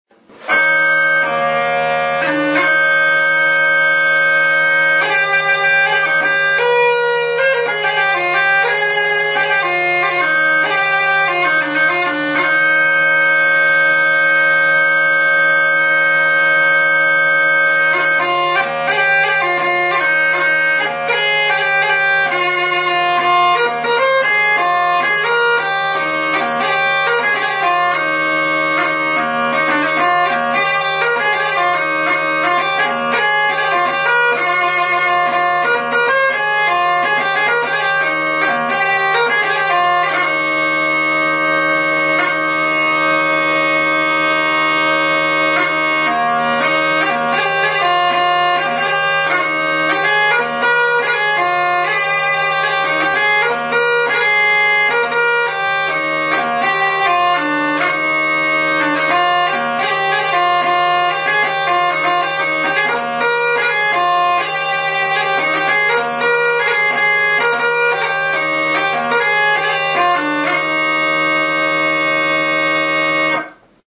CESKÉ DUDY; Bohemian "Bock" | Soinuenea Herri Musikaren Txokoa
Grabado con este instrumento.
Clasificación: Aerófonos -> Lengüetas -> Simple (clarinete) Aerófonos -> Lengüetas -> Cornamusa Situación: Erakusketa; aerofonoak